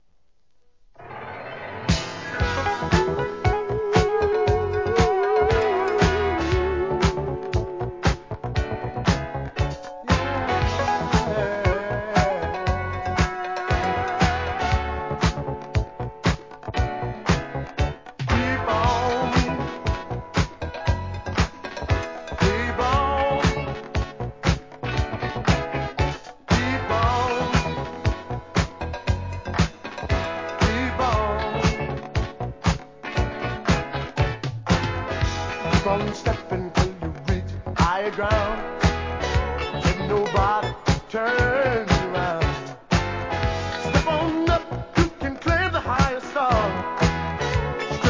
SOUL/FUNK/etc... 店舗 ただいま品切れ中です お気に入りに追加 1982年、名ダンスクラシック!!!